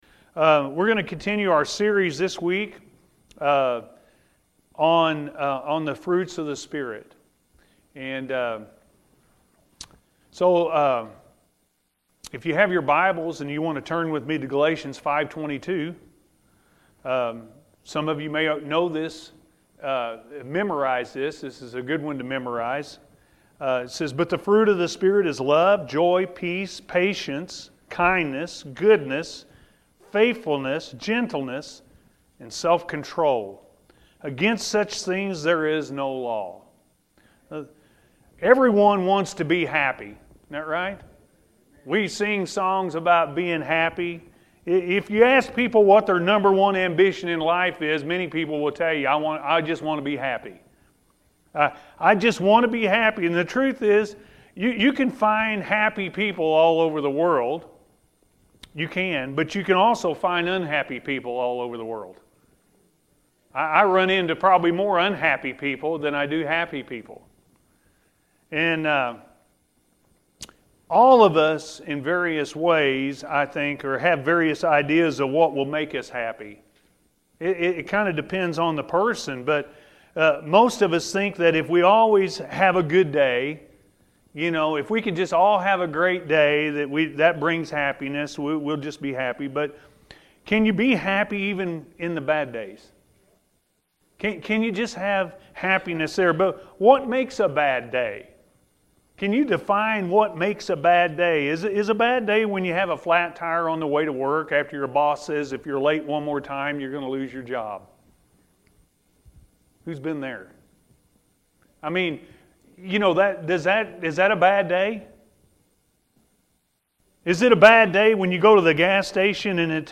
Joy In Your Heart Because God Is In Control-A.M. Service